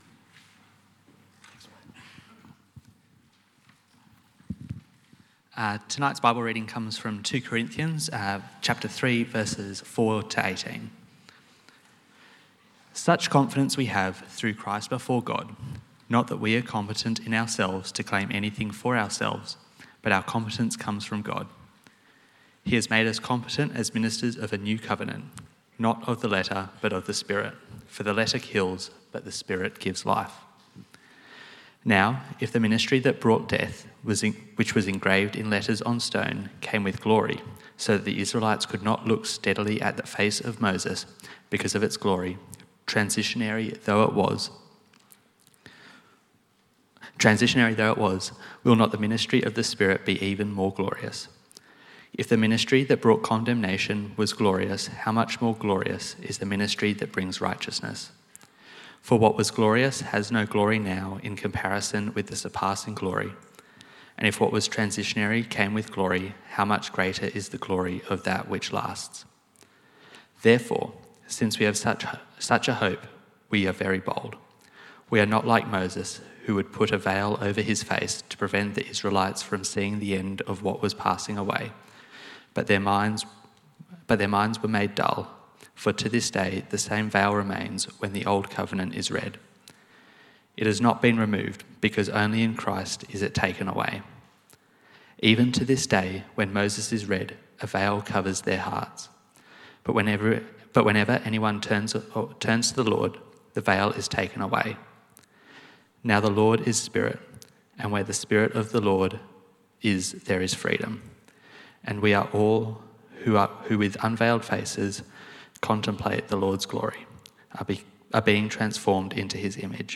Reflecting Jesus Preacher
2 Corinthians 3:4-18 Service Type: 6PM What would Jesus do in my situation?